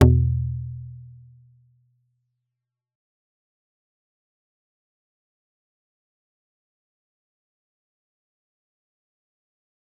G_Kalimba-E2-f.wav